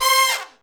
C5 POP FAL.wav